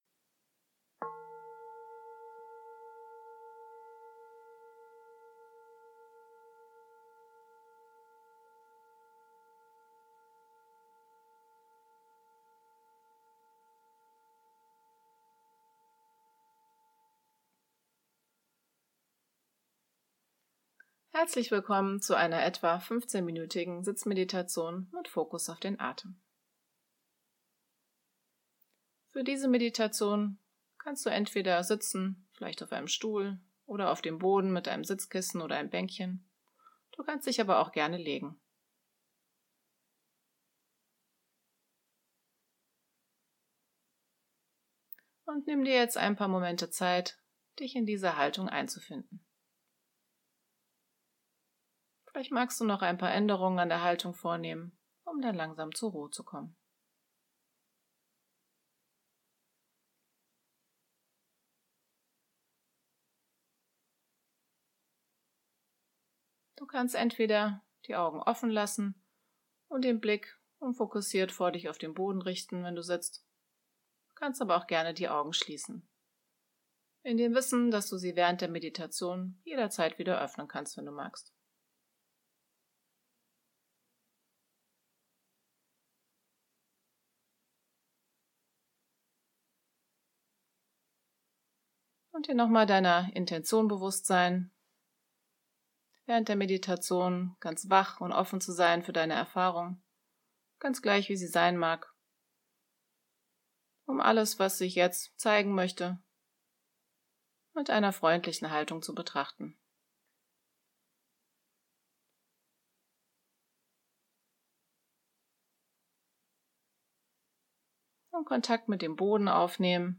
Download angeleitete Meditationen
15-minuten-sitzmeditation-mit-fokus-auf-den-atem.mp3